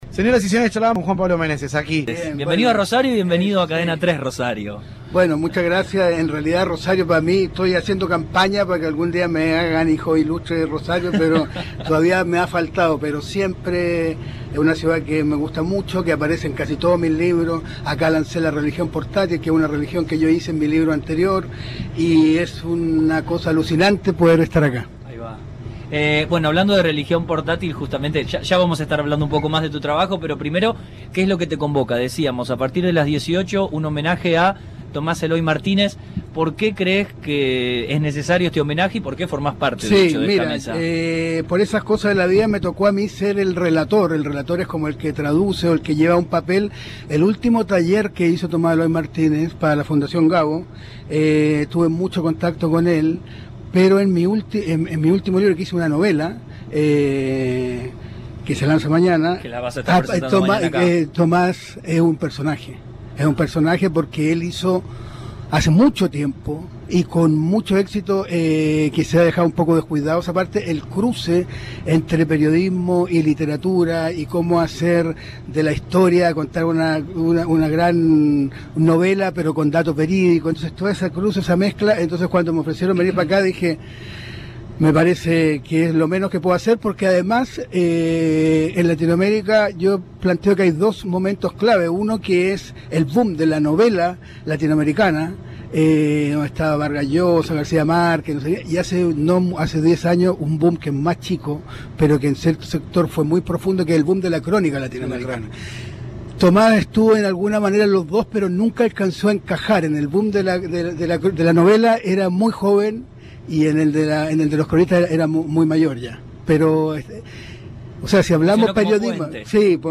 Entrevistado en el estudio móvil de Cadena 3 Rosario por su presentación en el evento cultural, el autor se refirió a su reciente novela, así como a su referencia en el icónico Tomás Eloy Martínez.